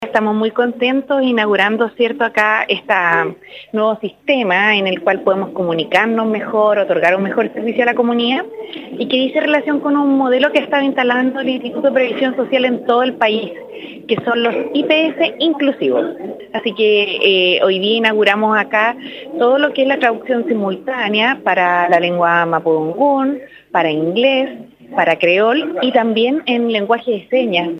Esta iniciativa nace a partir de un convenio que desarrolló el Instituto de Previsión Social (IPS) y el Departamento de Extranjería, el que permitirá que los extranjeros puedan realizar diversos trámites atendidos por el personal de esta oficina, tal como lo comento la Subsecretaría de Previsión Social, Janette Jara.
cuña-IPS-1.mp3